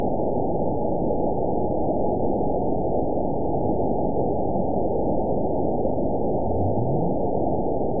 event 912330 date 03/24/22 time 19:47:09 GMT (3 years, 1 month ago) score 9.67 location TSS-AB01 detected by nrw target species NRW annotations +NRW Spectrogram: Frequency (kHz) vs. Time (s) audio not available .wav